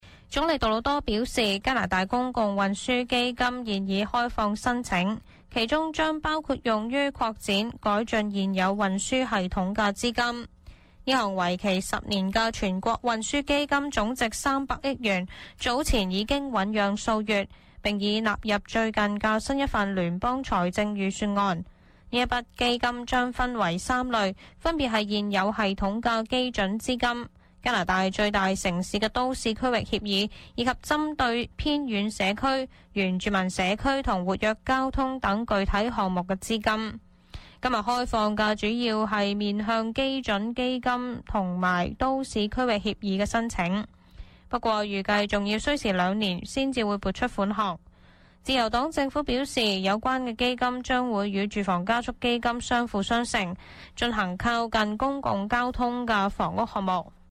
news_clip_19736.mp3